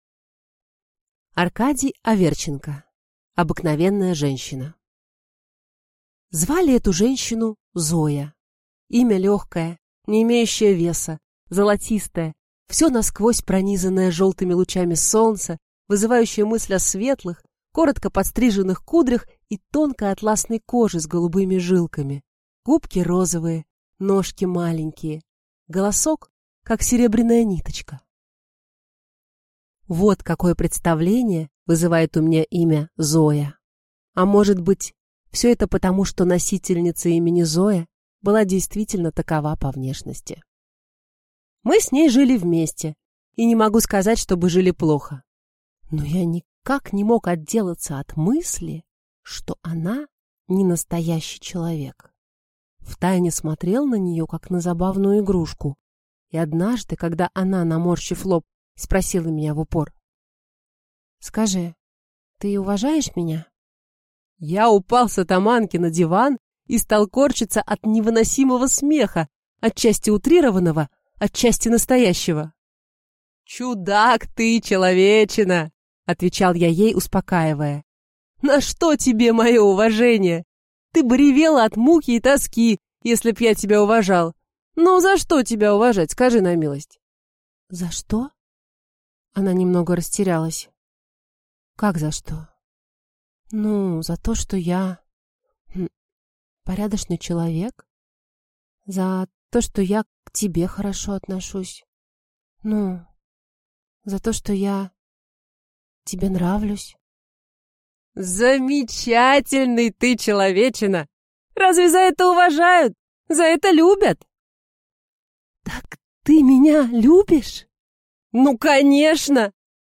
Аудиокнига Обыкновенная женщина